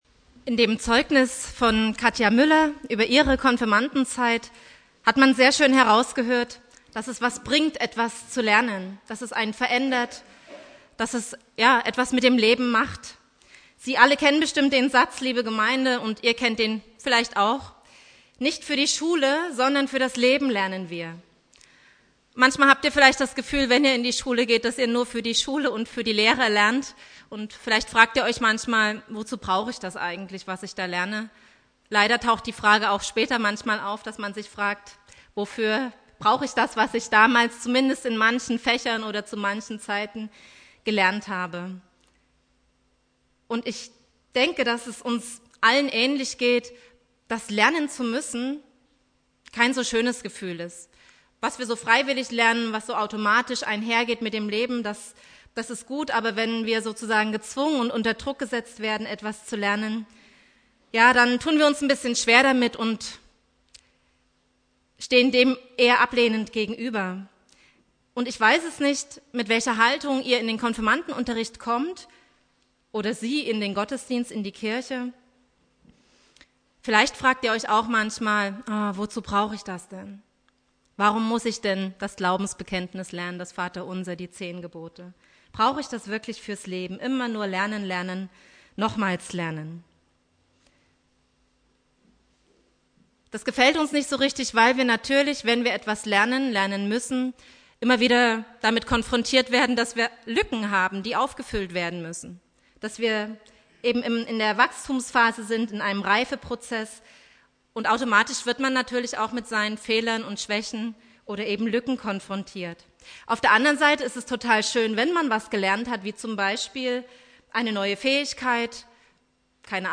Thema: Jesus und die kanaanäische Frau Inhalt der Predigt